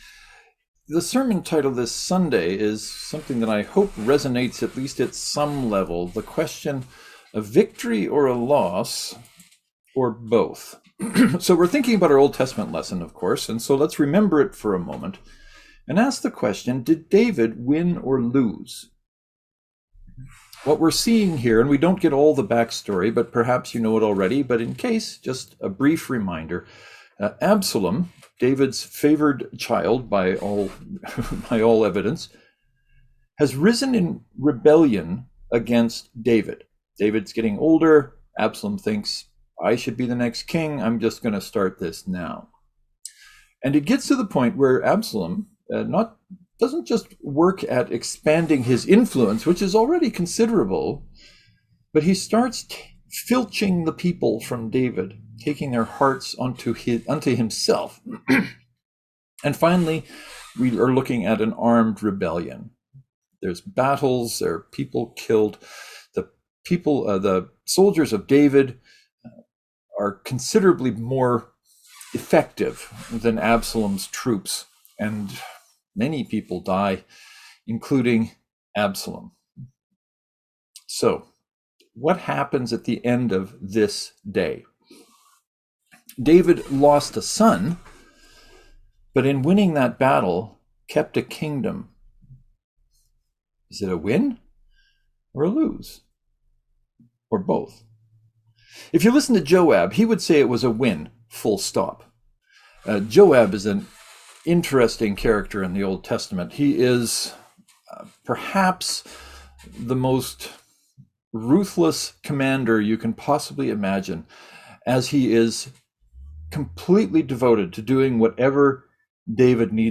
This Sunday’s sermon is looking at how we can come out of these desperately difficult situations.